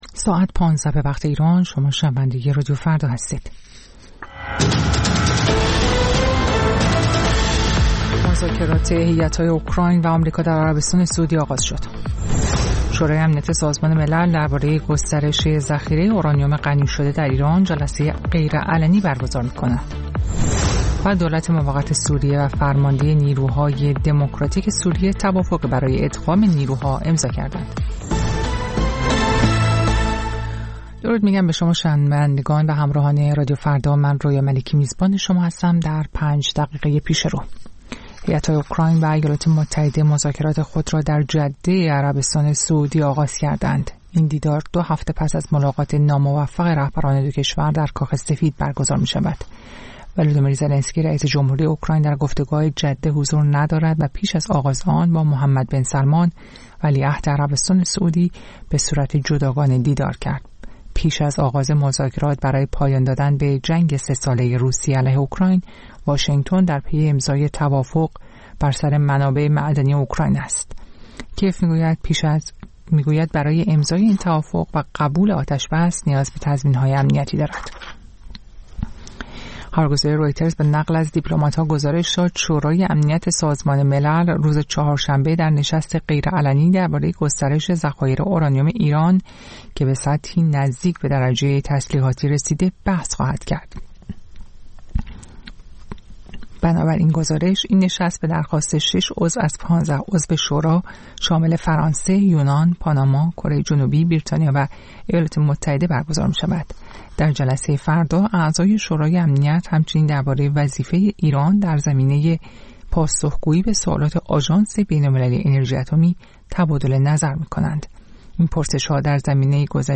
سرخط خبرها ۱۵:۰۰